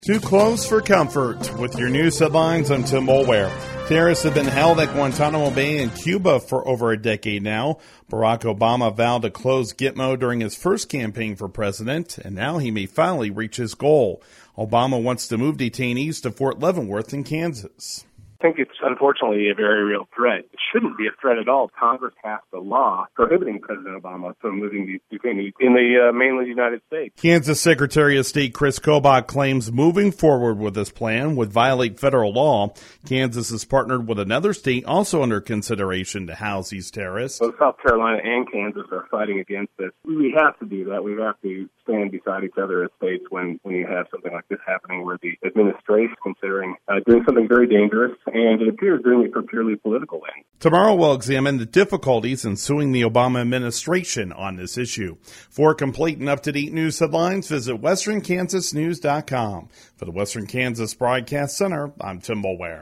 *On-air story*